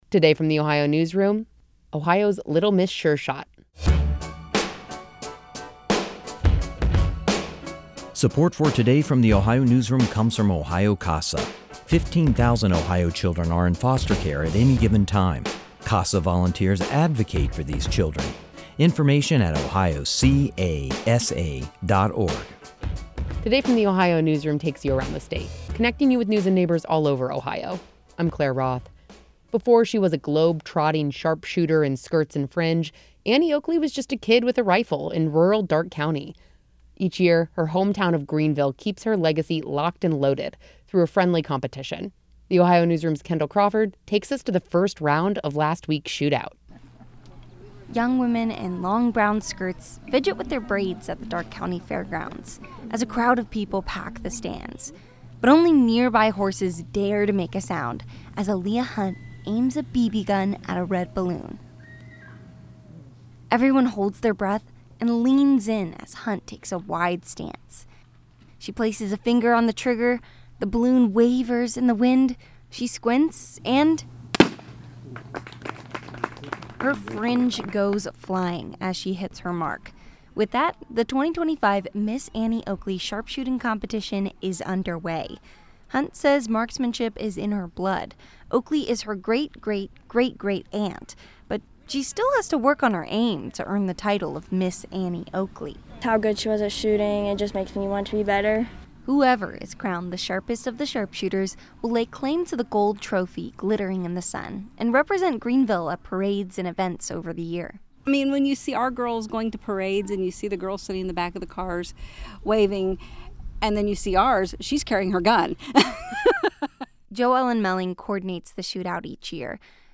Young women in long brown skirts fidgeted with their braids, as a crowd of people packed the stands at the Darke County Fairgrounds.
Then the silence breaks with a sharp pop.